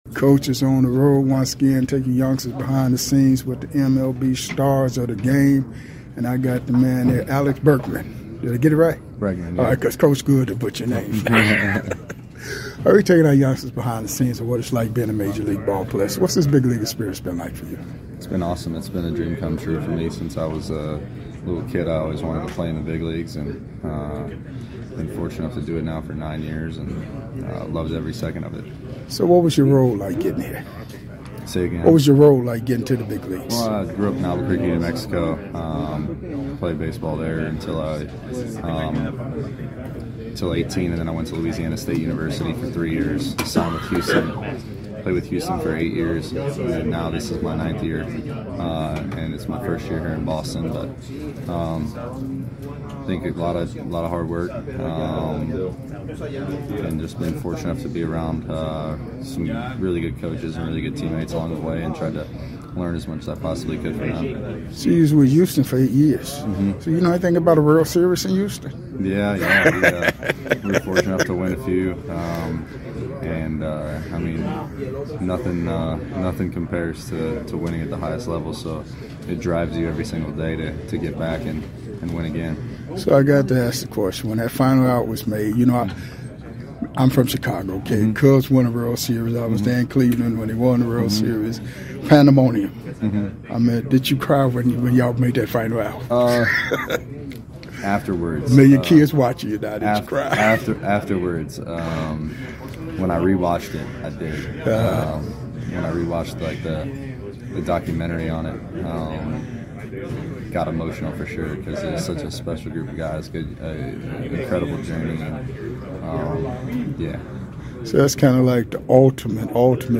⚾ MLB Classic Interviews